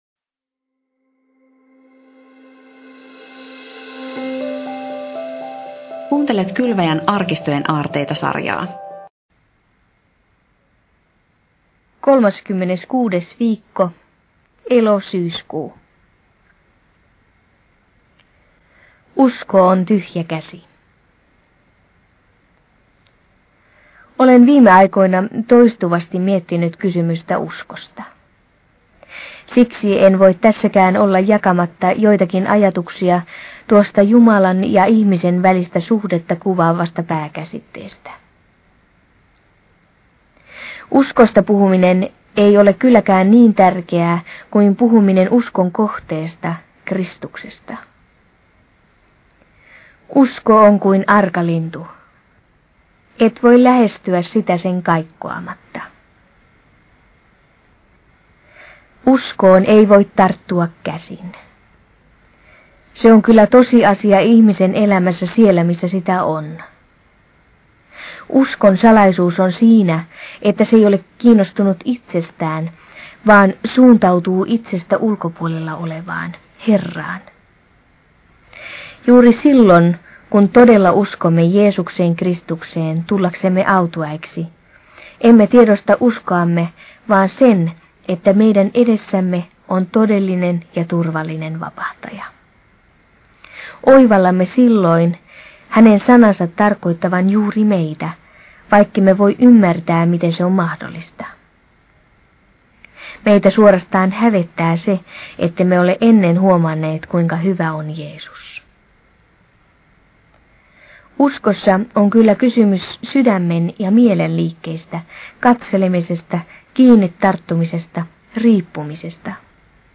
Lukija